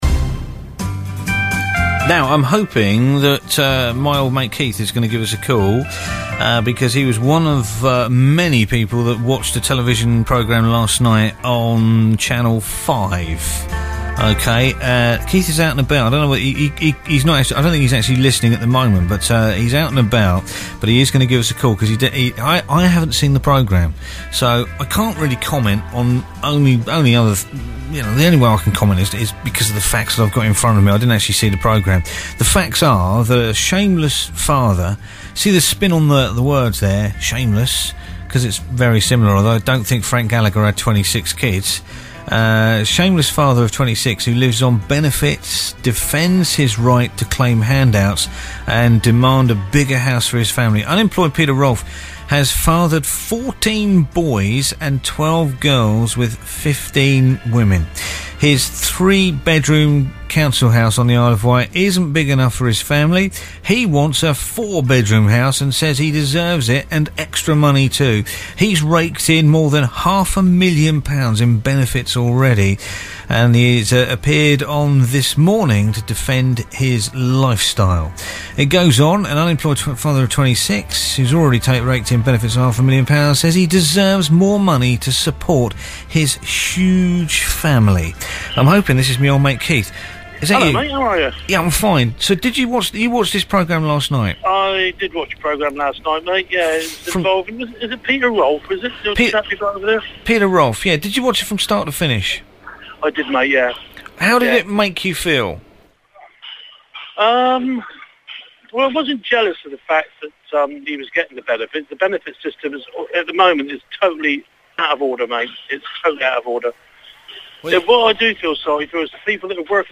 This Weeks Tuesday Phone In